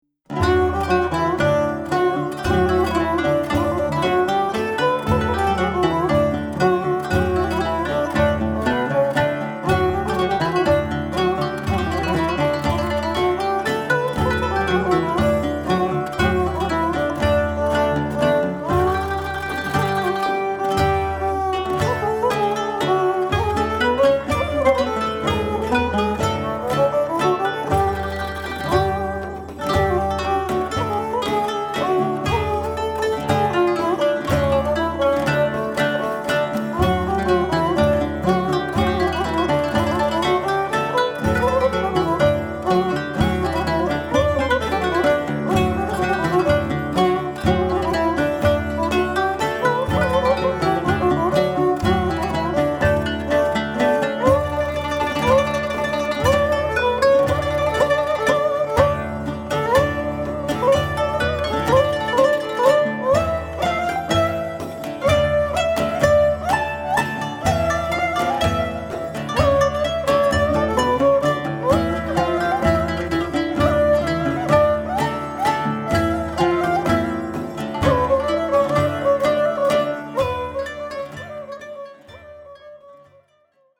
Genre: Turkish Traditional.
kemençe (left channel)
kemençe (right channel) & lavta
violoncello
kanun
daire
bendir
Recorded in November 1999 in Istanbul